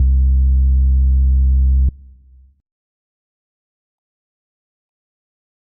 808s
Bass (4).wav